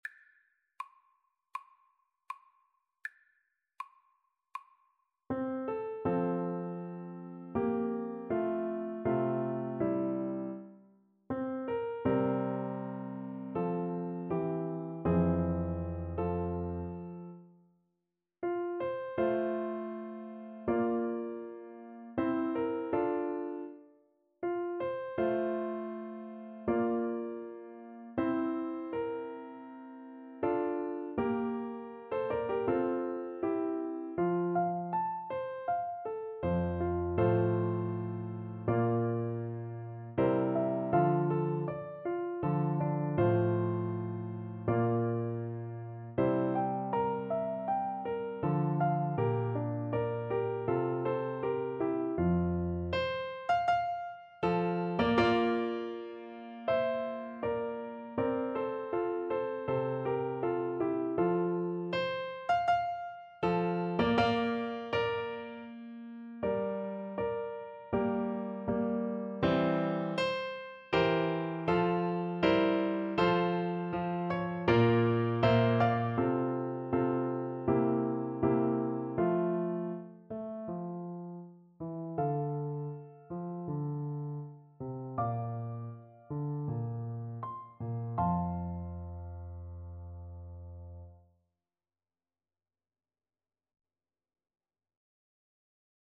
Play (or use space bar on your keyboard) Pause Music Playalong - Piano Accompaniment Playalong Band Accompaniment not yet available transpose reset tempo print settings full screen
F major (Sounding Pitch) G major (Trumpet in Bb) (View more F major Music for Trumpet )
4/4 (View more 4/4 Music)
Andante
Classical (View more Classical Trumpet Music)